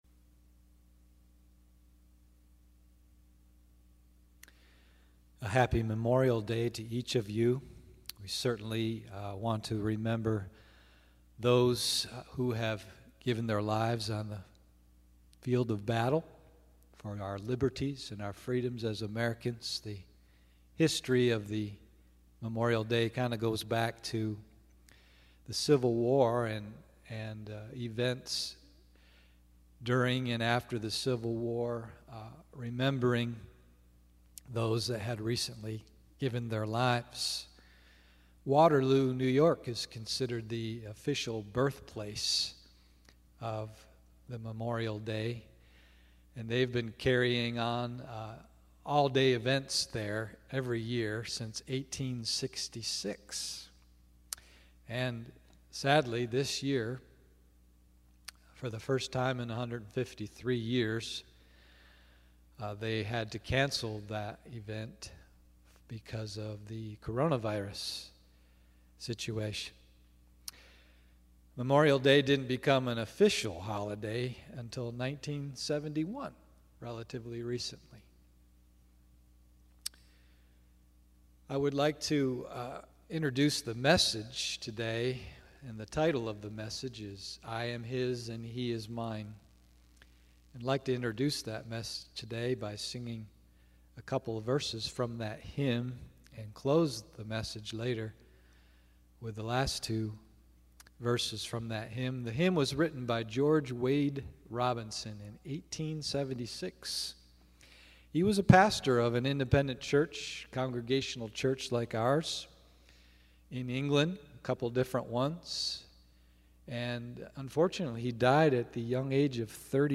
Special Music and Message